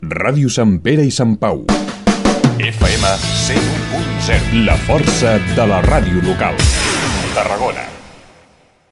Identificació de l'emissora
FM